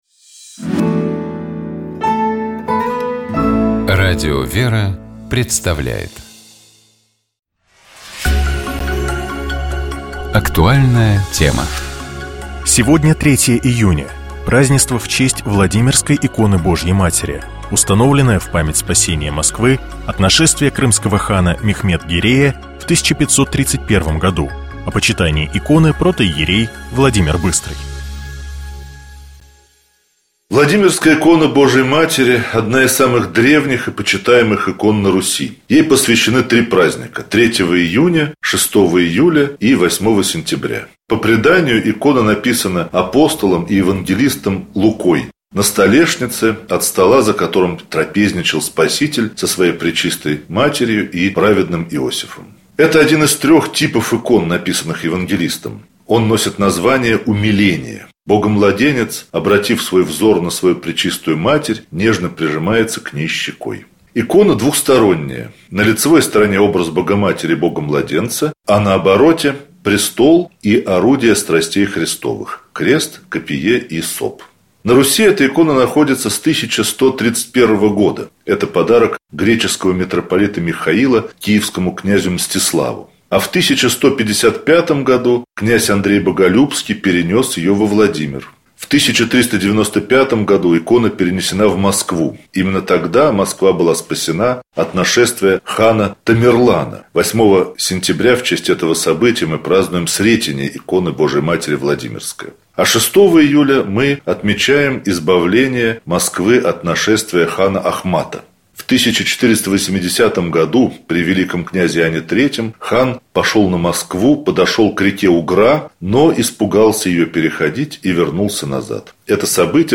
Псалом 26. Богослужебные чтения Скачать 16.02.2026 Поделиться Помазание на царство — древнейший чин наделения человека властью.